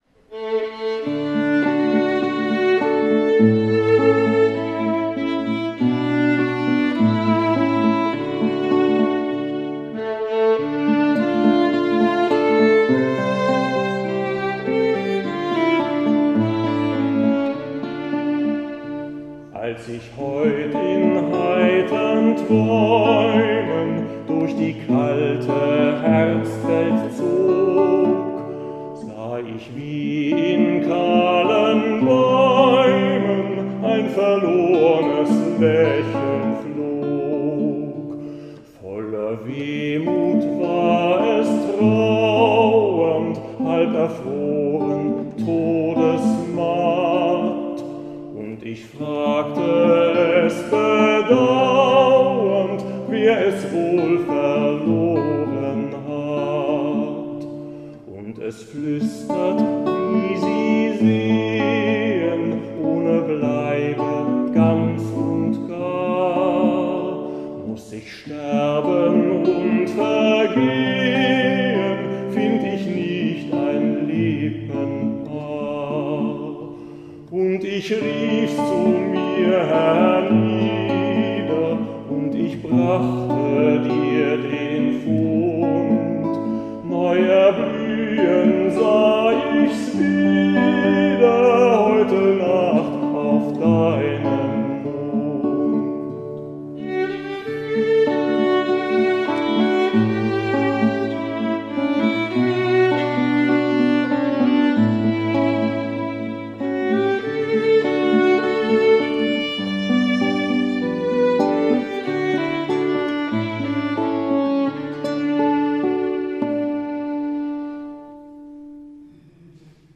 Live-Mitschnitt Kunstnacht 2024 & Probenschnipsel
Gitarre
live Kunstnacht Augsburg 2024